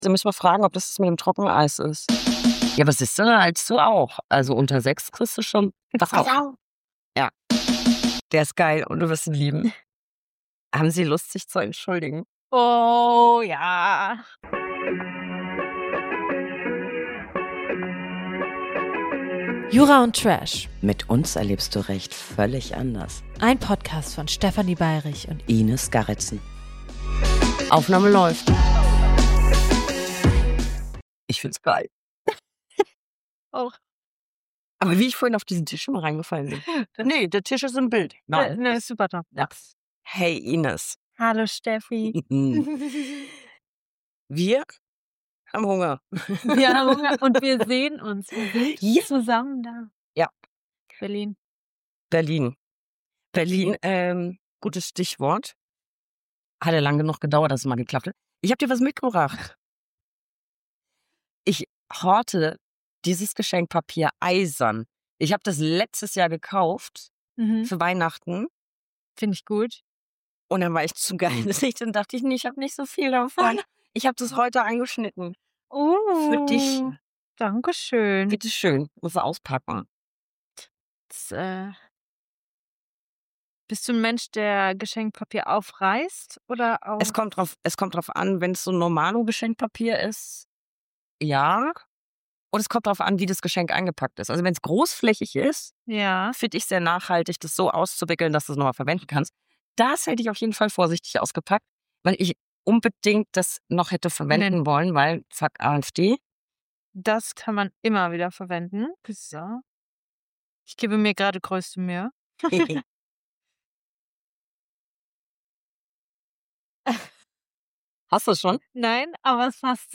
Liebste Grüße an das Roji Berlin, die extra für uns die Musik leiser gedreht und uns den großen Tisch für die Aufnahme reserviert haben!